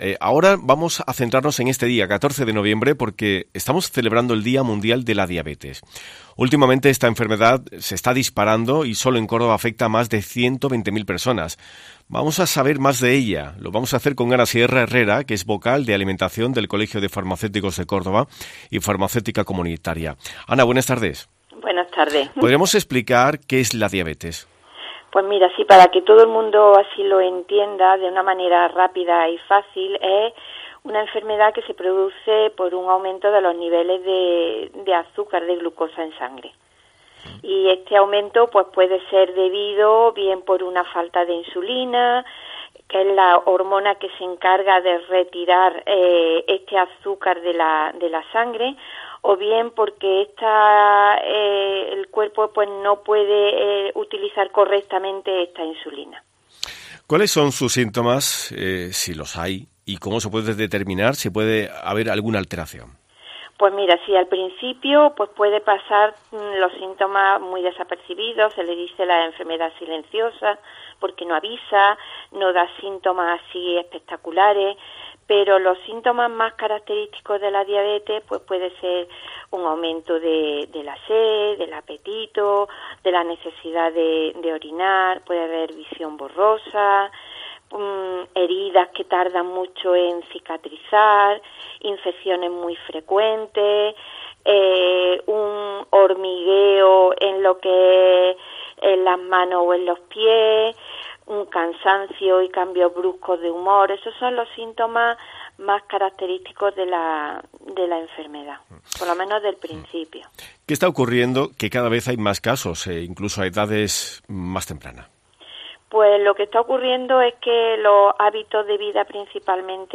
Escucha a tres profesionales hablar sobre la diabetes